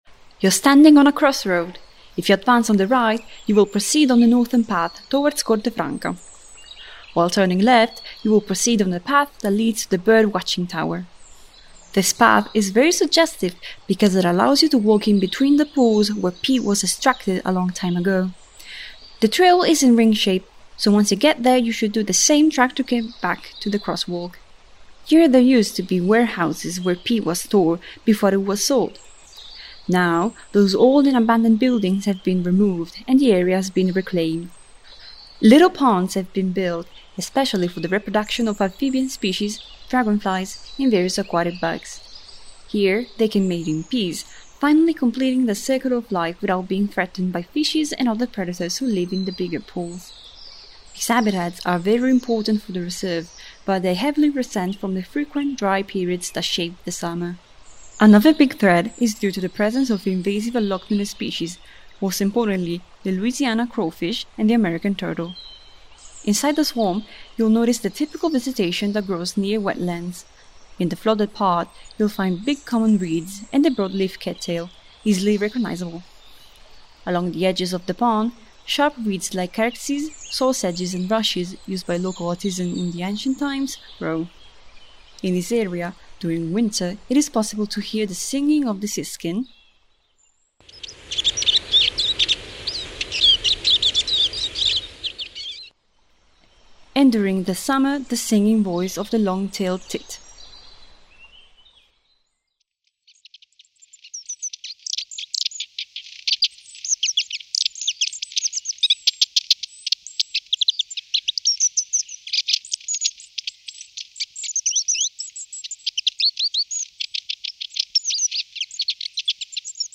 Audioguide 5